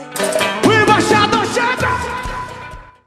embaixador-vinheta.mp3